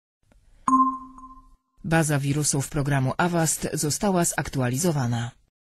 sound that preceded first crashes after crowdstrike definition update (1 attachments)